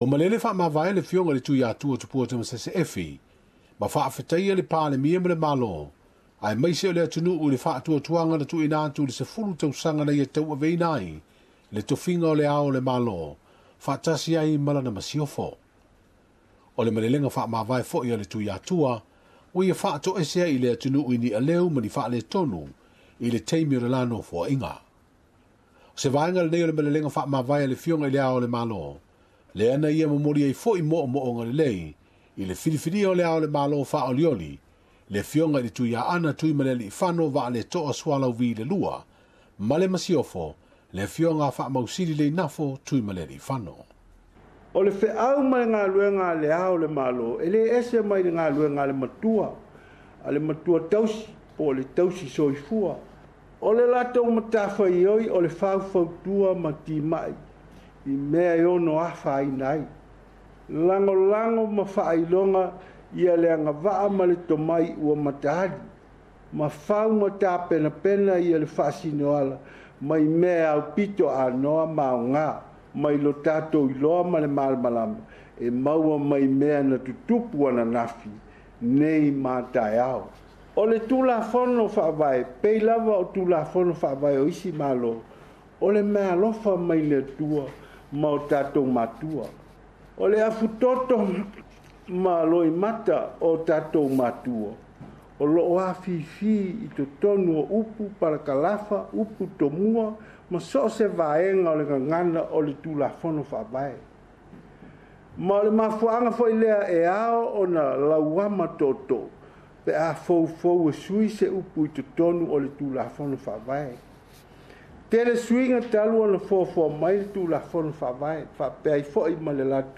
Malelega faamavae a le Ao o le Malo Samoa Tui Atua Tupua Tamasese Efi